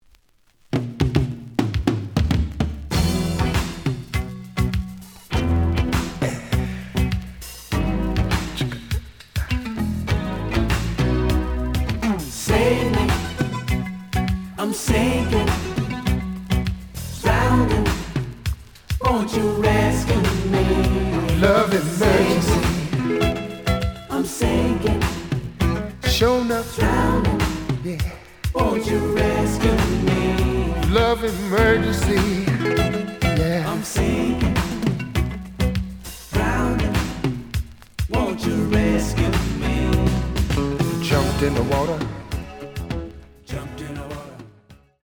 試聴は実際のレコードから録音しています。
●Genre: Soul, 80's / 90's Soul
●Record Grading: VG~VG+ (A面のラベルに書き込み。盤に若干の歪み。プレイOK。)